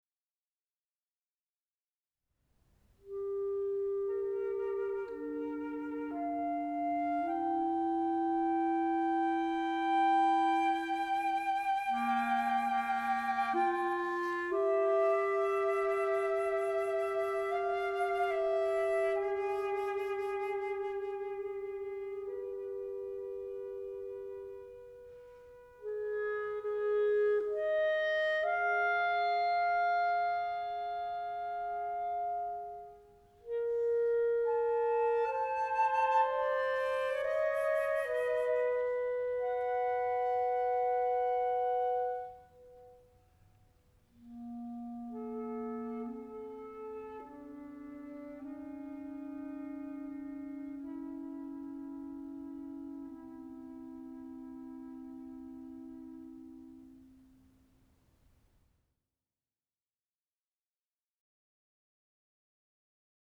Flute & Clarinet
2. Slowly and freely with rubato